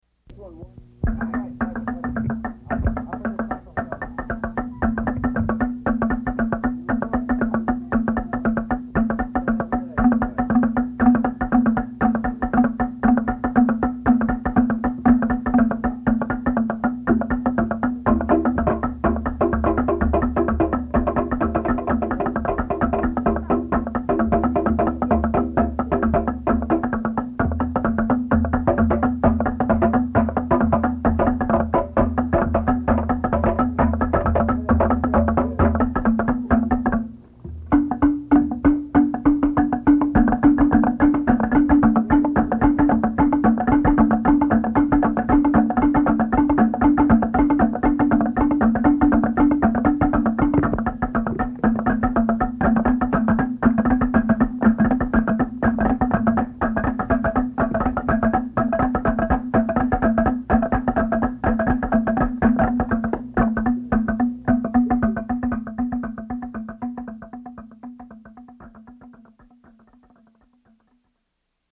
Inspelningarna är gjorda på fältet
Tamboo bamboo band  - föregångaren till steelband.